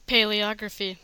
Ääntäminen
IPA : /ˌpalɪˈɒɡɹəfi/